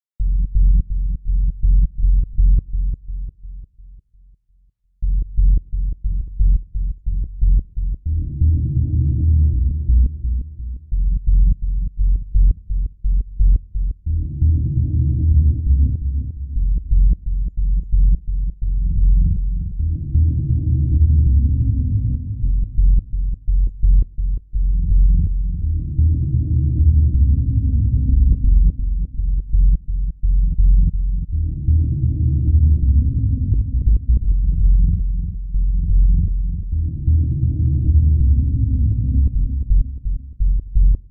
描述：电子贝斯的砂砾状脉动悸动
Tag: 线头 低音 电子 砂砾 脉搏 悸动